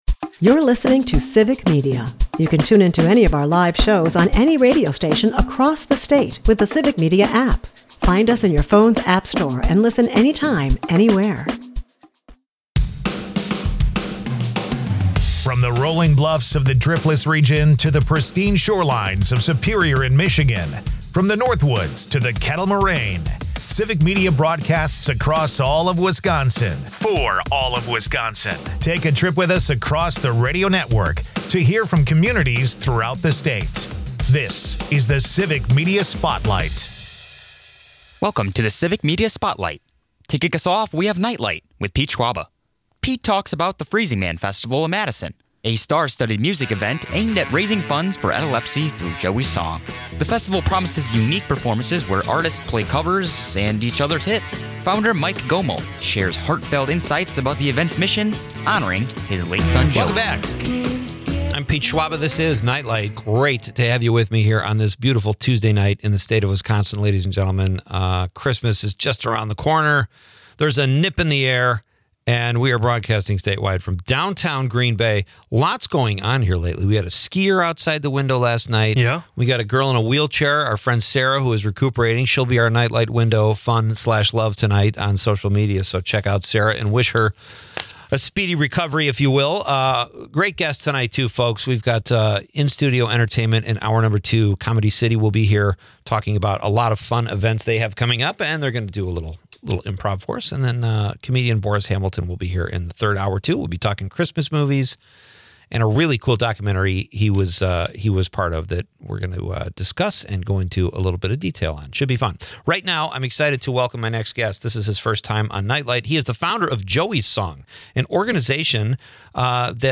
The discussion takes a humorous and pointed turn when a caller from Madison drops a reality check about who’s really handing out the iPads.